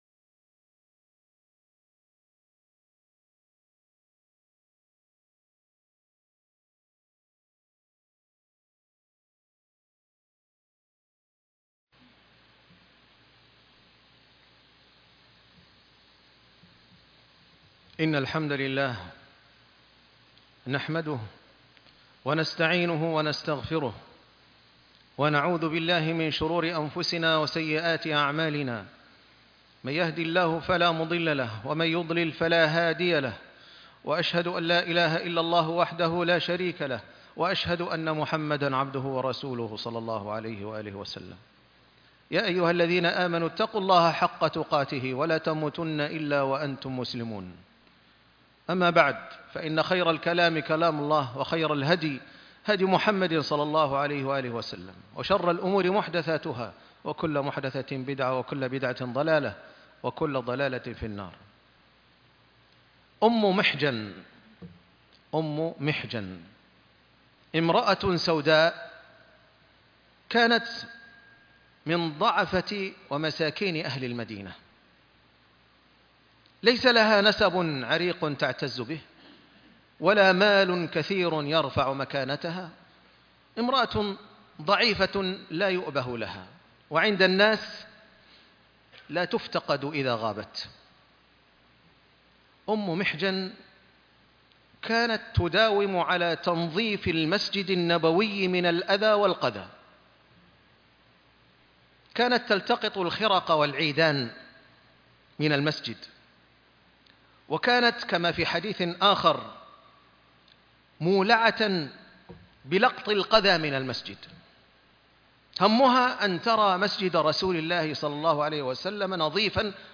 الإهتمام - خطبة الجمعة